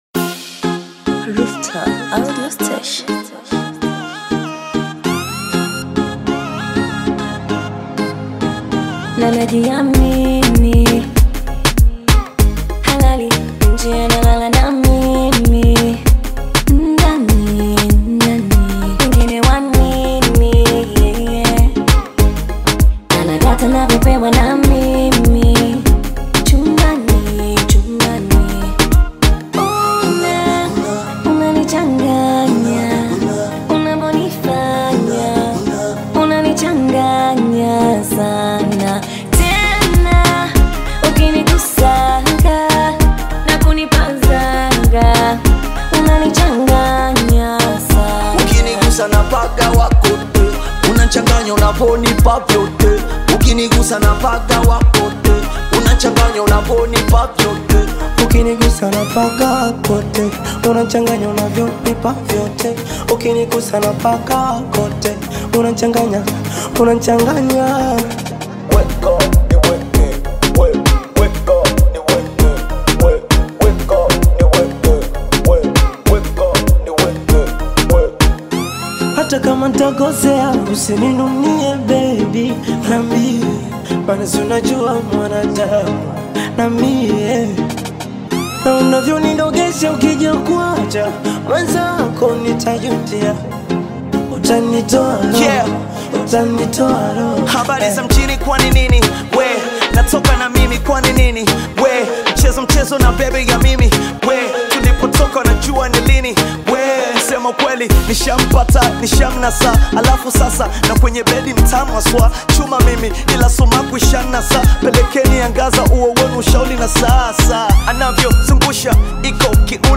Tanzanian female artist, singer
beautiful heartfelt song